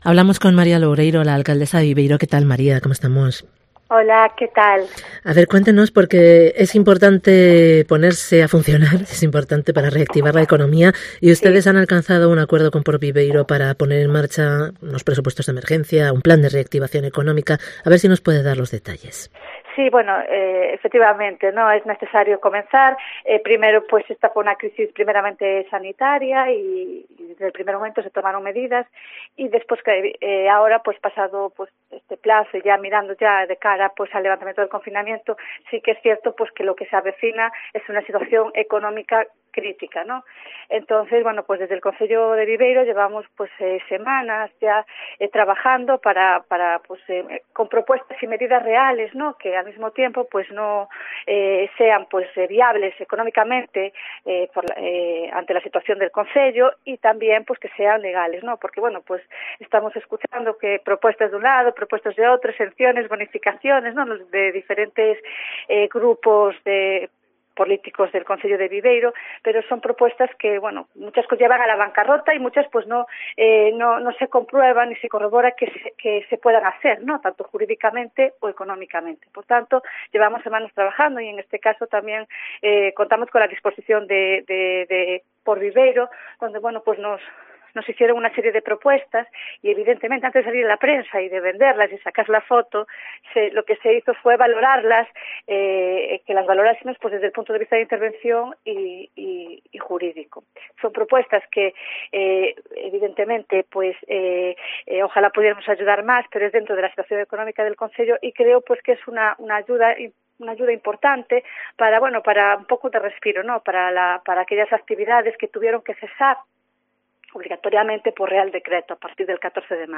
Entrevista con MARÍA LOUREIRO, alcaldesa de Viveiro